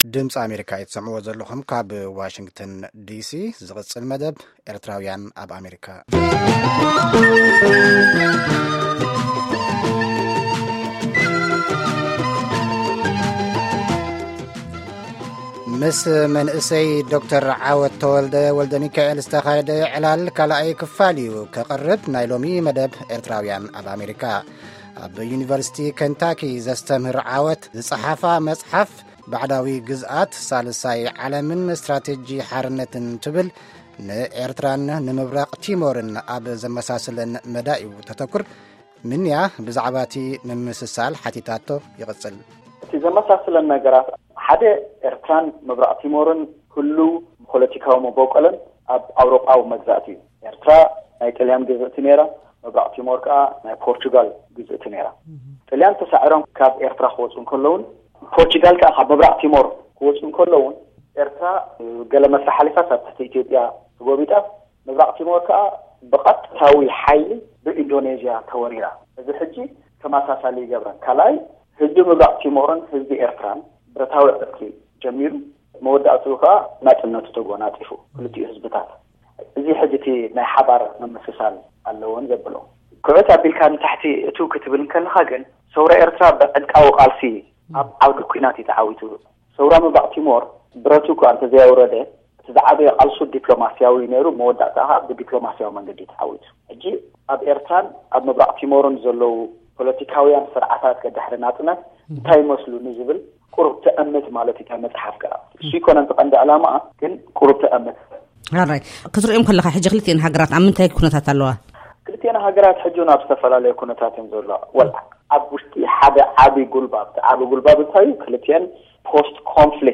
ቃለመጠይቕ